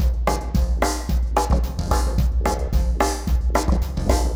RemixedDrums_110BPM_44.wav